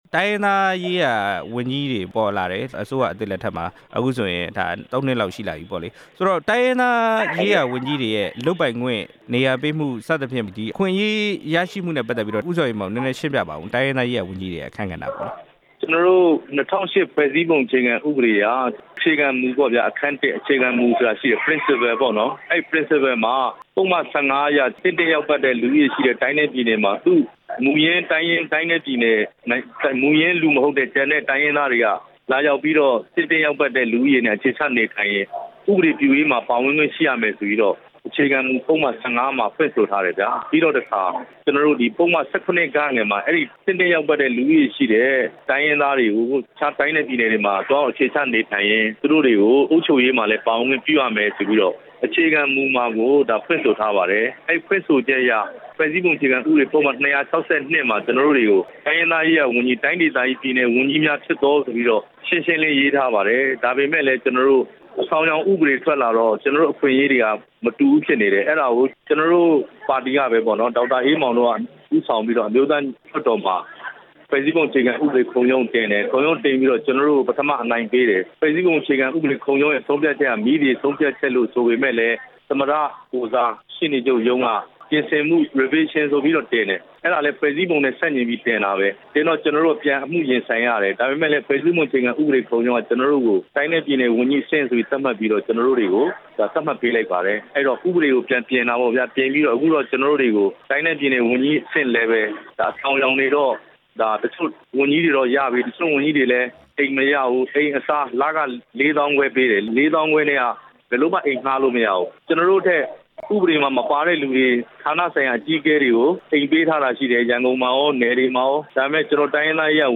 တိုင်းရင်းသားရေးရာ ဝန်ကြီးတွေရဲ့ လုပ်ပိုင်ခွင့် ဆက်သွယ်မေးမြန်းချက်